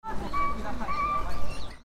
The voices of many wild birds, like Japanese nightingales and
pheasants, were heard as usual year.